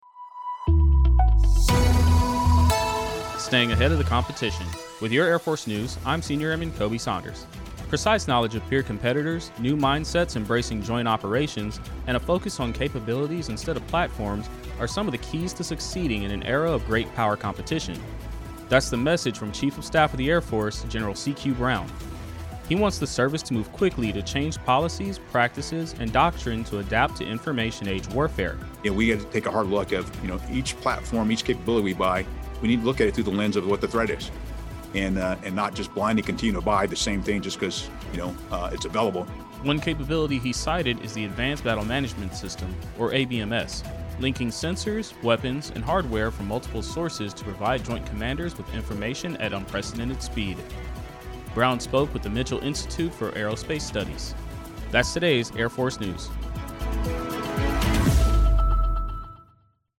Air Force Radio News 26 October 2020